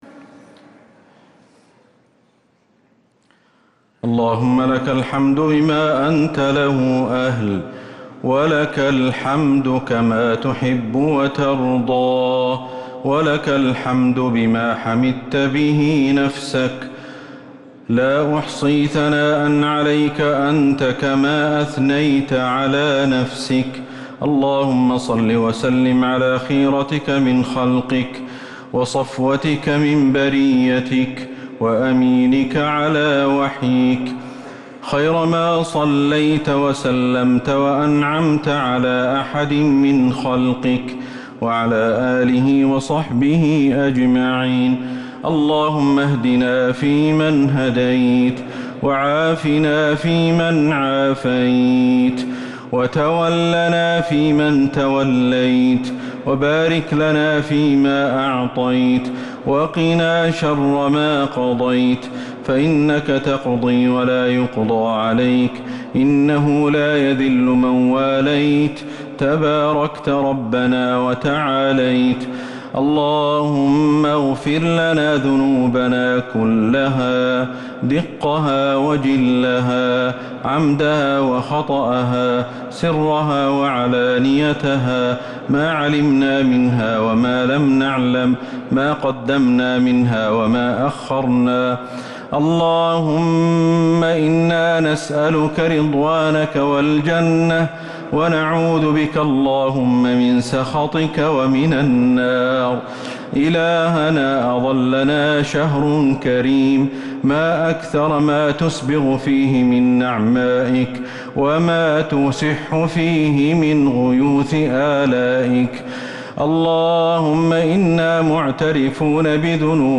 دعاء القنوت ليلة 1 رمضان 1444هـ | Dua 1st night Ramadan 1444H > تراويح الحرم النبوي عام 1444 🕌 > التراويح - تلاوات الحرمين